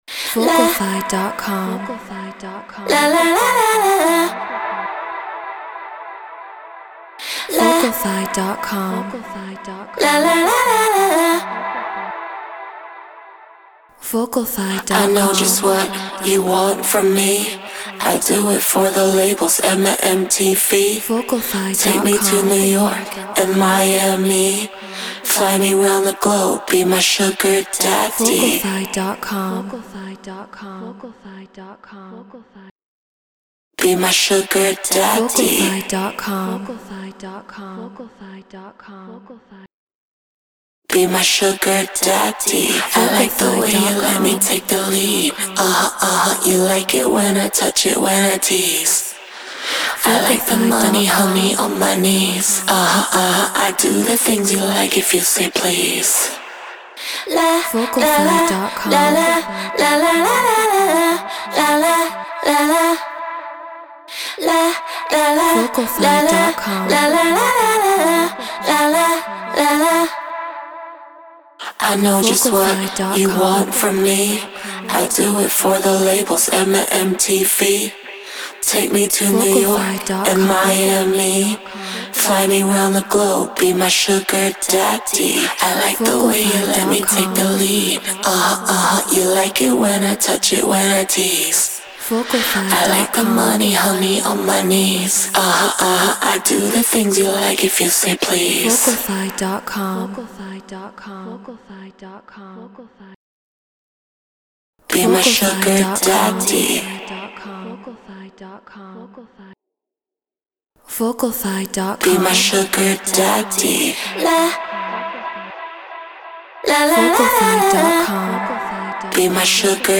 Tech House 135 BPM Emin
Treated Room